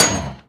Minecraft Version Minecraft Version snapshot Latest Release | Latest Snapshot snapshot / assets / minecraft / sounds / mob / blaze / hit2.ogg Compare With Compare With Latest Release | Latest Snapshot